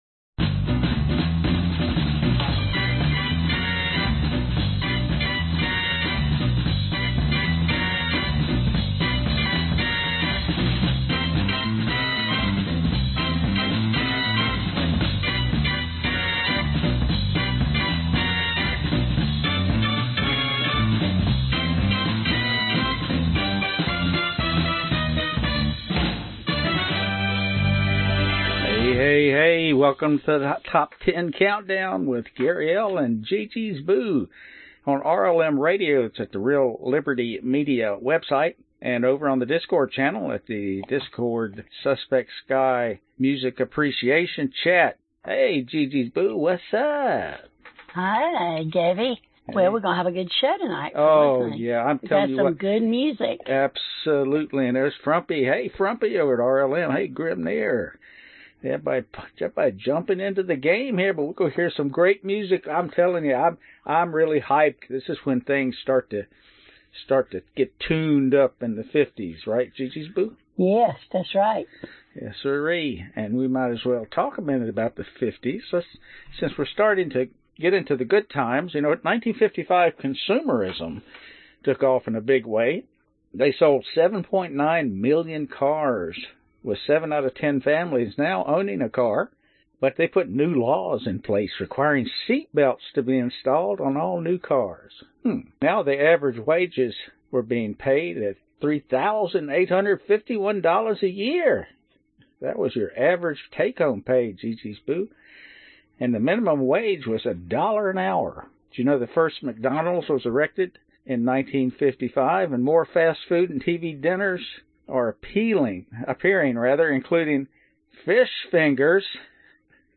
1953 Genre Oldies Year 2021 Duration 1:02:27